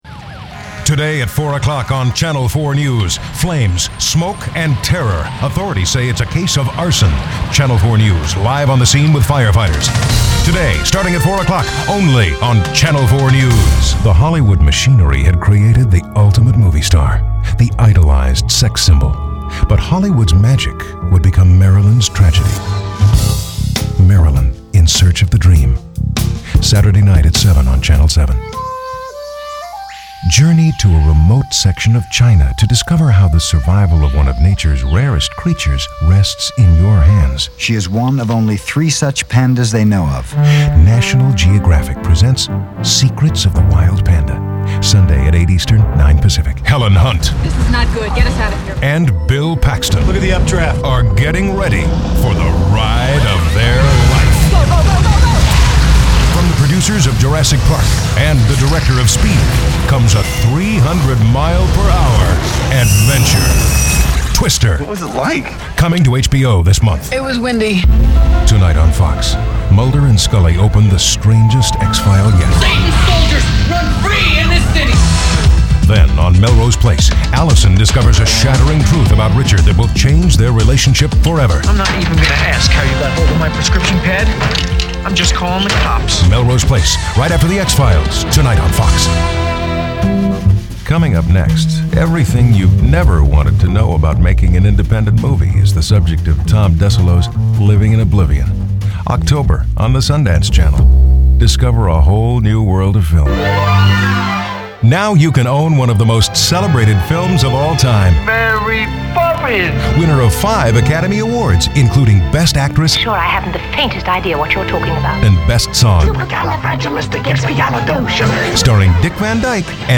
Promos & Trailers Demo [MP3 file]
promos.mp3